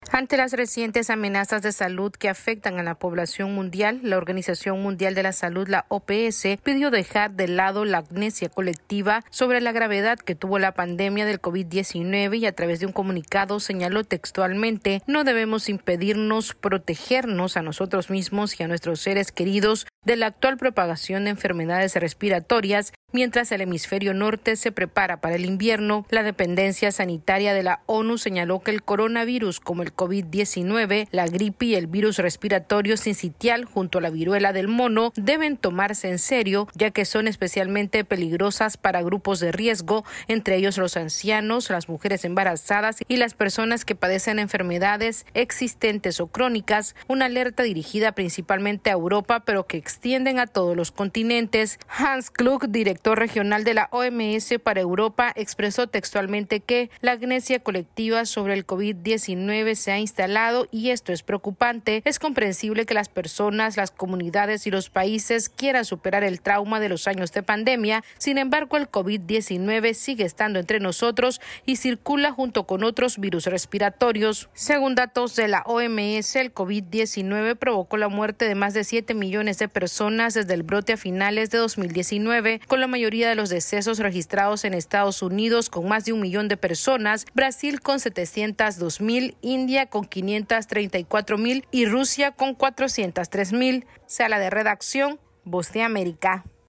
La Organización Mundial de la Salud pidió acabar con la "amnesia colectiva" sobre el fuerte impacto de la pandemia del COVID-19. Esta es una actualización de nuestra Sala de Redacción...